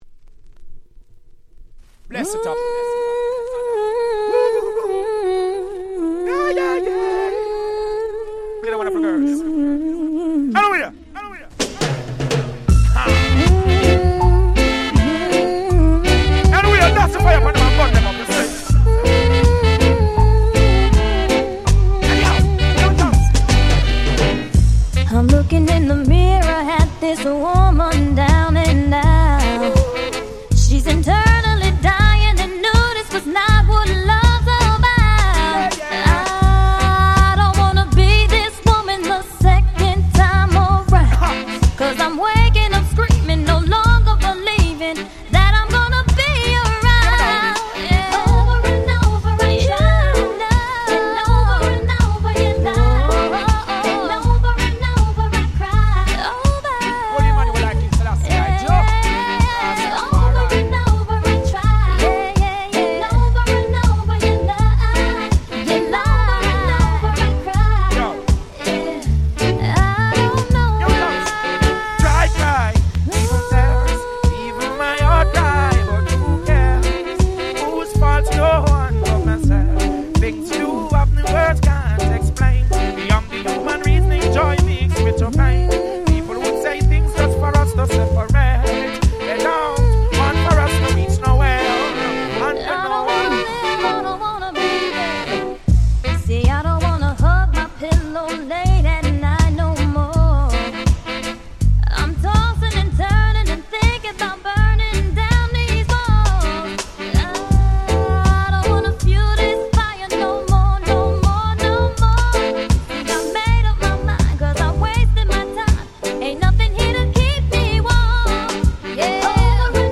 Reggaeの非常に使えるマッシュアップを全5トラック収録した1枚！！